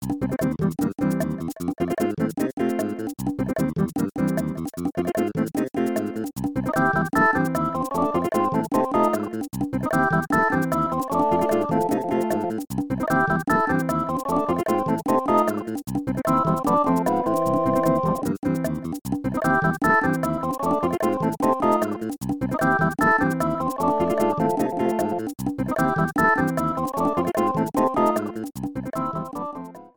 Clipped to 30 seconds and applied fade-out.